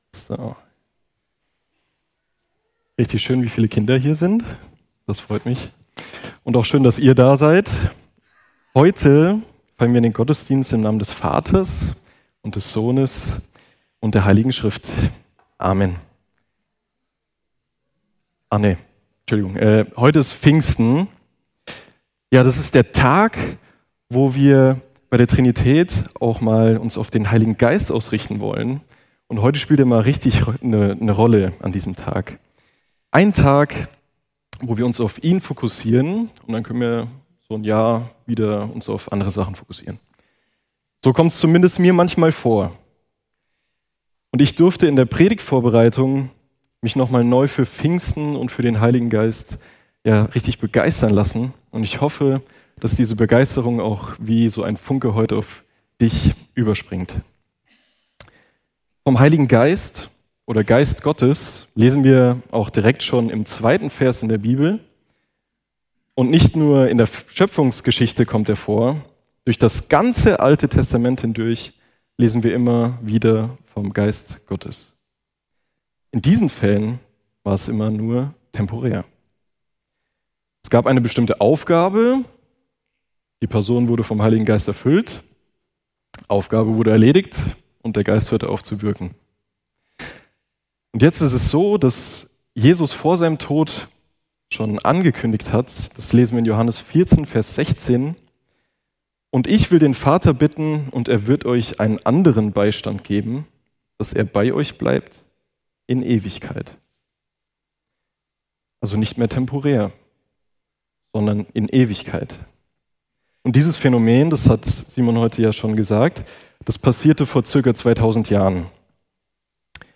Predigen und andere Vorträge